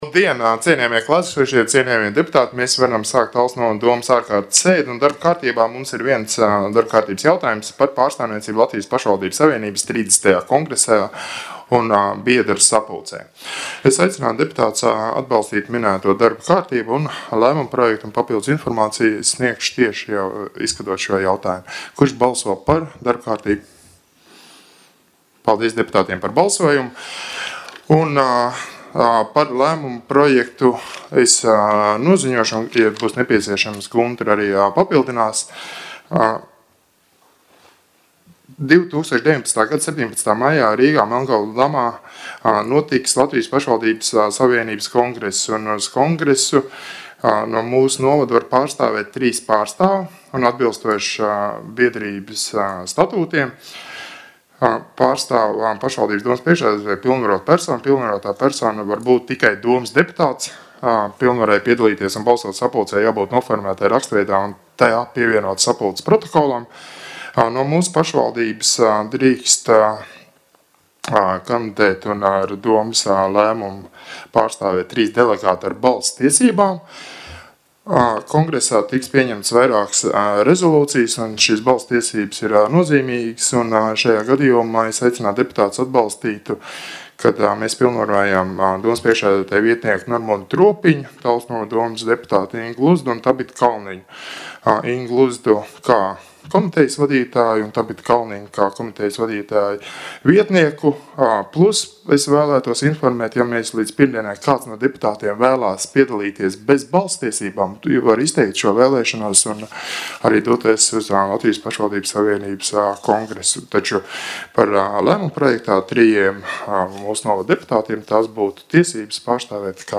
Domes sēde